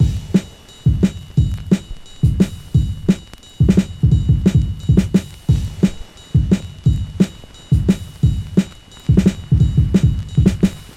描述：hard quantized loop made with a Roland MC303 (this is not a factory pattern)
标签： breakbeat jungle loop
声道立体声